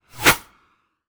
bullet_flyby_09.wav